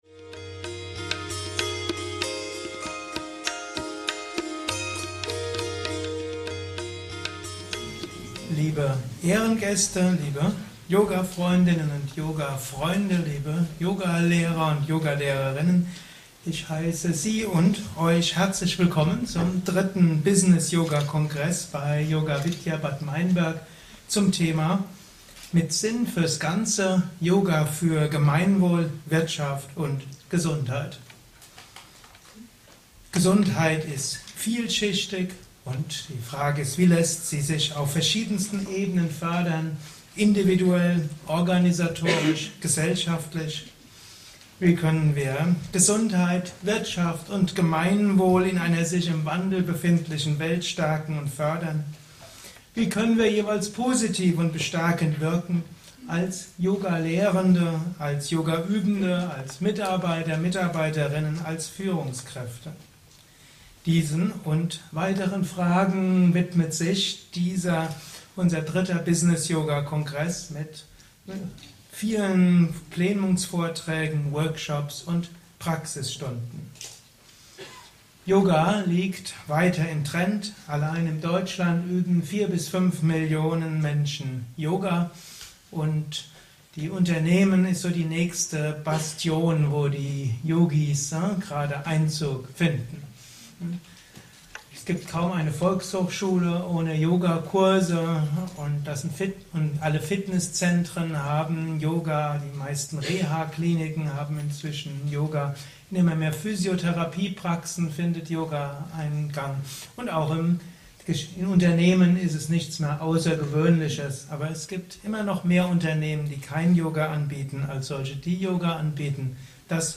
Der 3. Business Yoga Kongress mit dem Motto “Mit Sinn fürs Ganze –